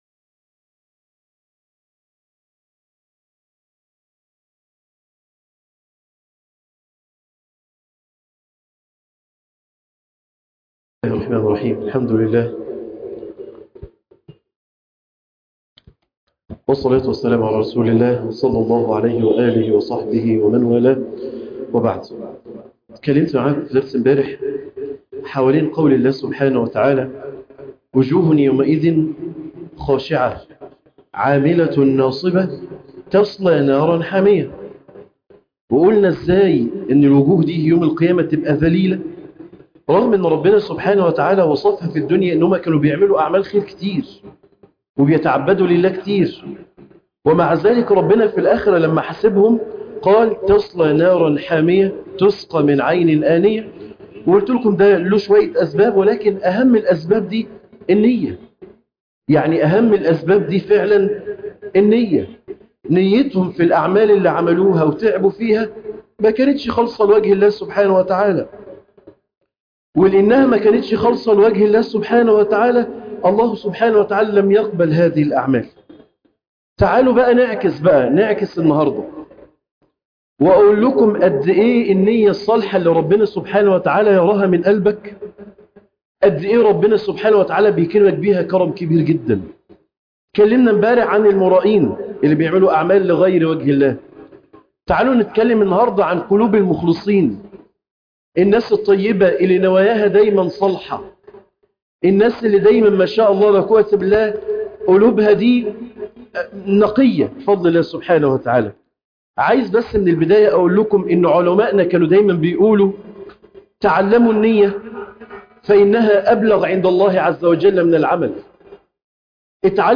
النية هتفرق كتير جدا في حياتك - موعظة قصيرة